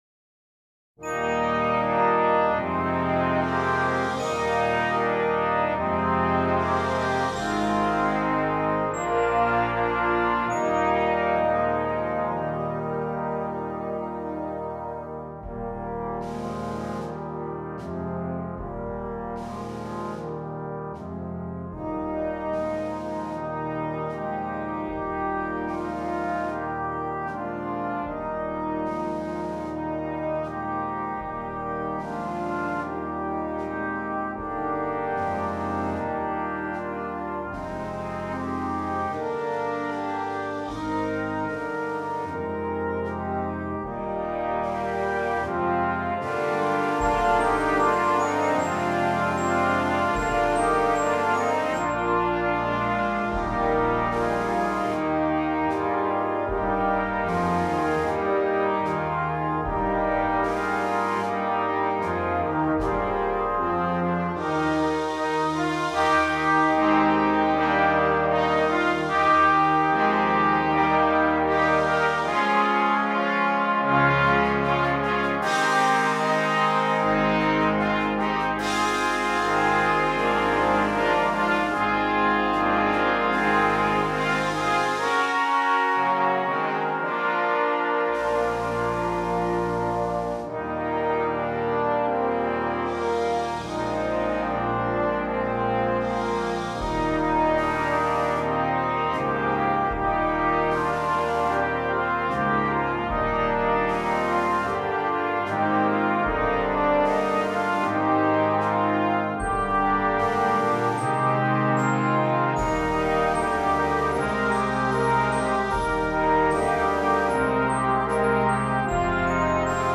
Brass Choir (3.2.2.1.1.perc)
colourful and harmonically interesting arrangement